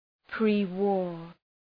Προφορά
{,pri:’wɔ:r}